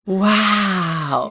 Звуки речи, голоса, пения
английская женщина средних лет восклицает вау